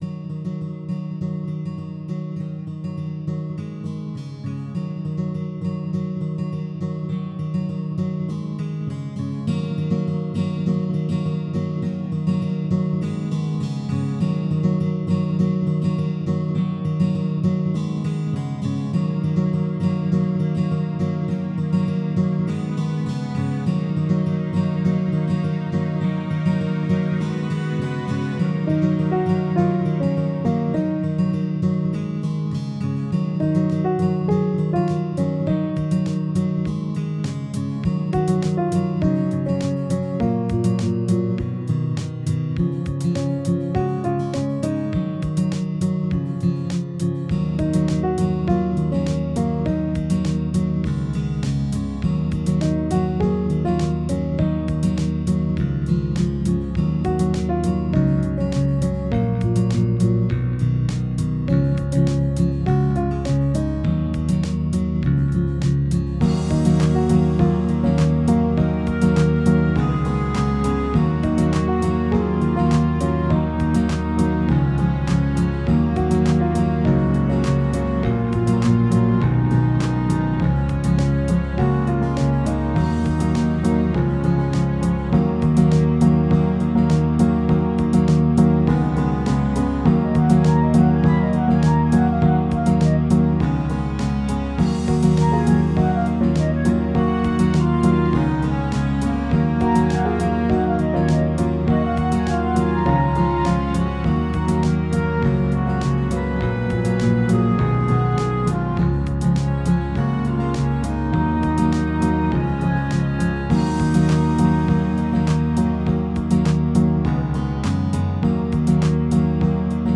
Tags: Piano, Clarinet, Woodwinds, Strings, Guitar, Percussion